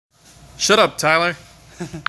PLAY Quiet sound effect
shut-up3234.mp3